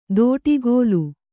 ♪ dōṭigōlu